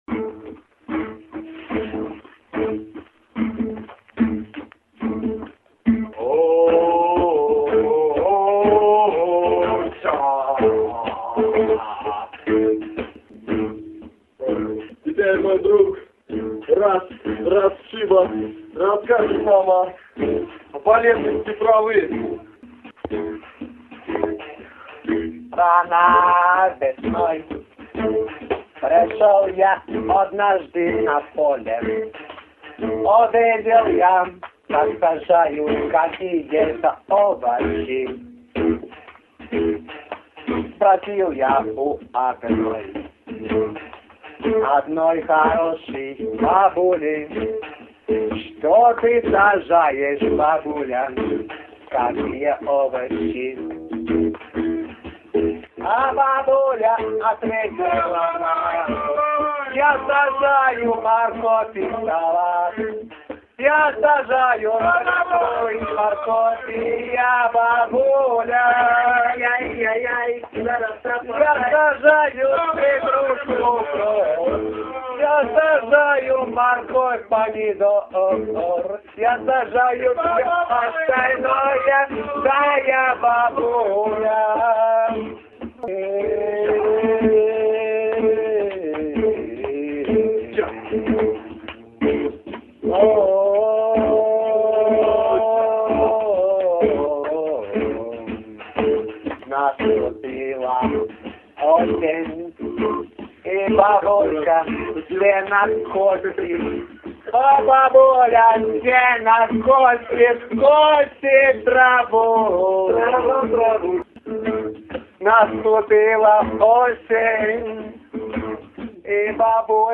По весне собираемся с однокурсниками и чего-нибудь записываем.
В этом году получилось нечто психоделическое и выносящее мозг .